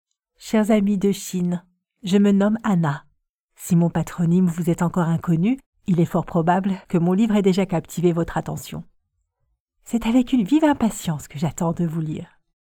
Never any Artificial Voices used, unlike other sites.
Female Voice Over Talent, Artists & Actors
Yng Adult (18-29) | Adult (30-50)